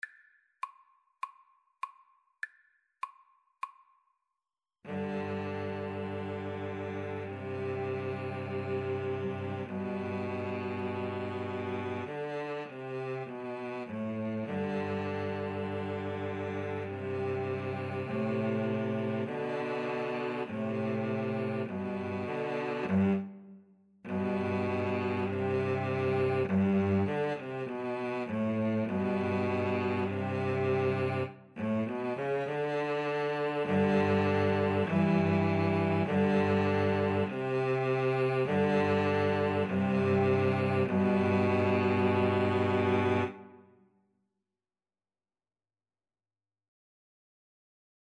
G major (Sounding Pitch) (View more G major Music for Cello Trio )
Espressivo
Cello Trio  (View more Intermediate Cello Trio Music)